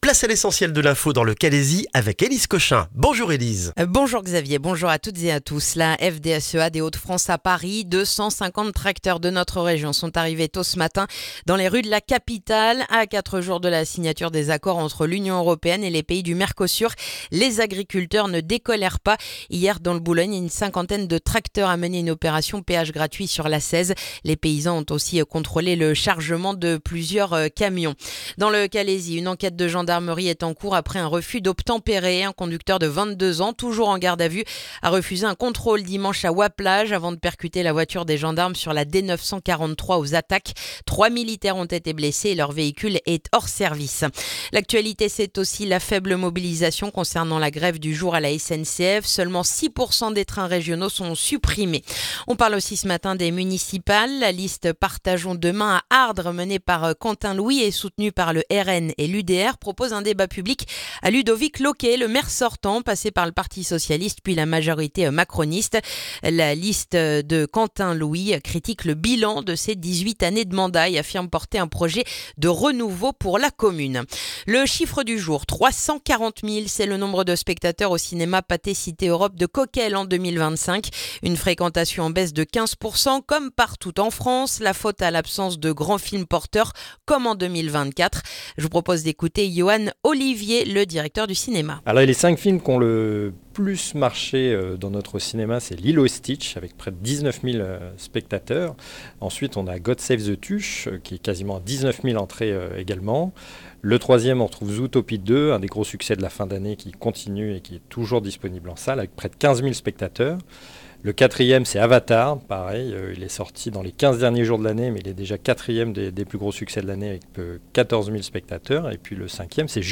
Le journal du mardi 13 janvier dans le calaisis